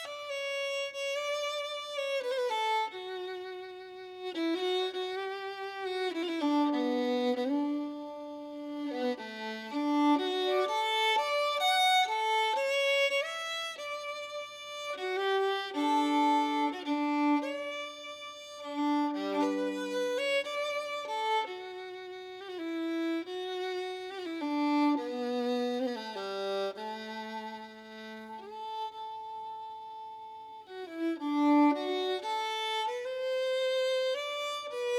Жанр: Рок / Кантри / Фолк-рок